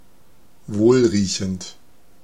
Ääntäminen
IPA : /swiːt/